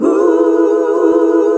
HUH SET E.wav